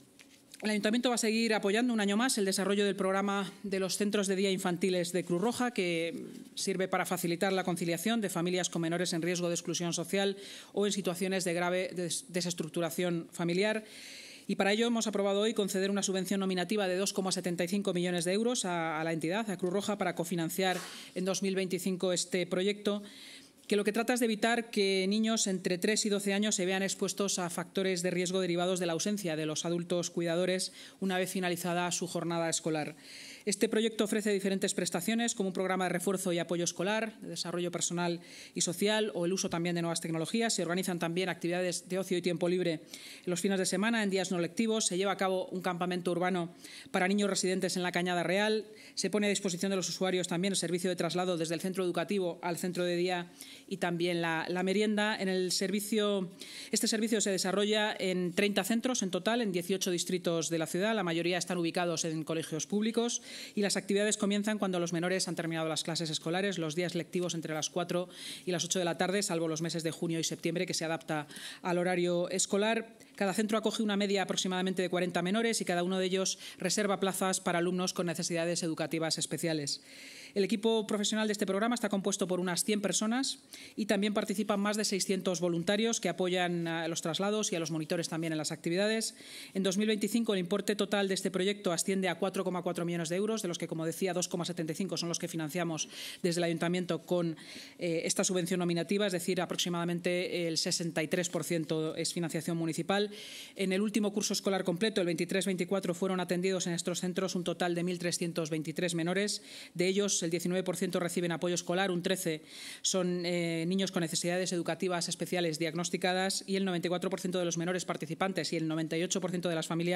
Nueva ventana:La vicealcaldesa y portavoz municipal, Inma Sanz: